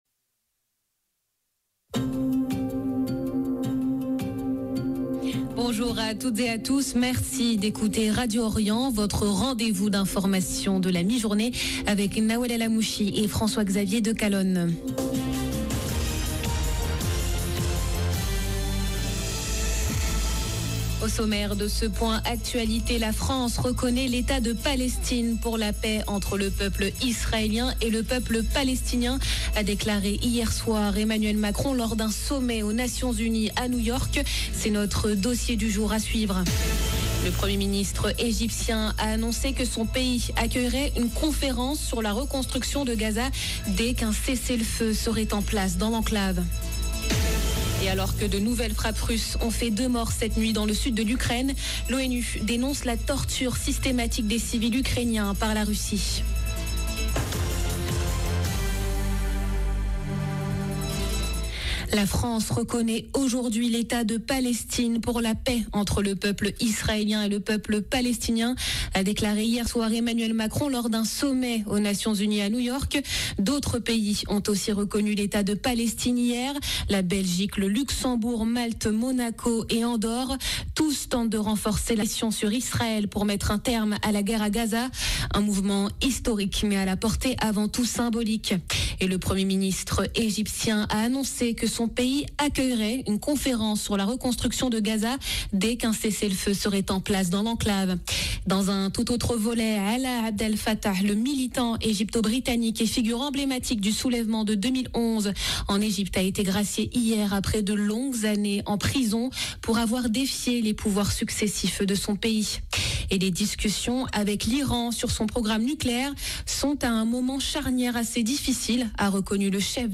Journal de midi du 23 septembre 2025